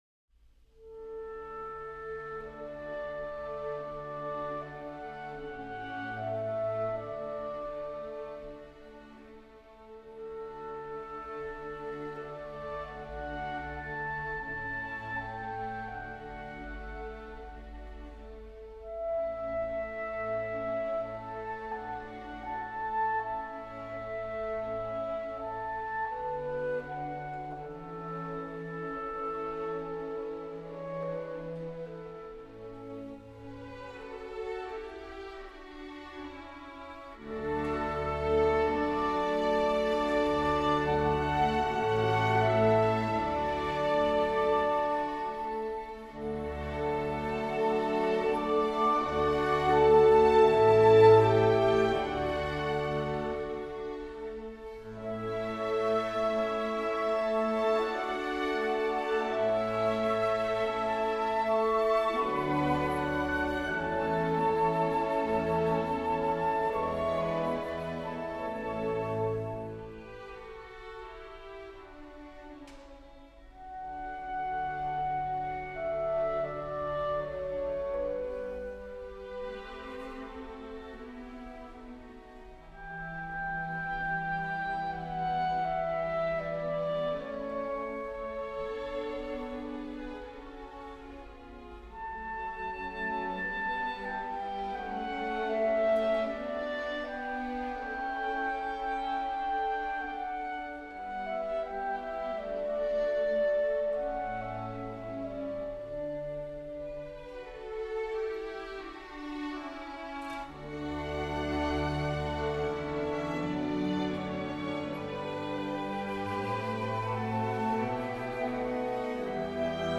Clarinetto
adagio.mp3